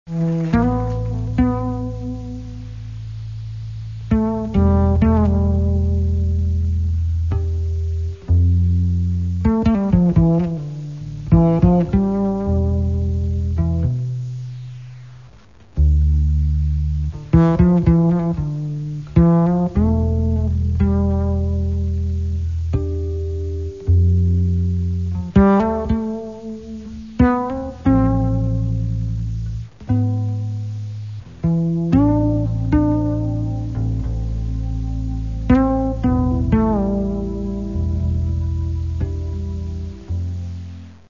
Джаз и около
бас-гитара
тенор-саксофон
клавишные
гитара
барабаны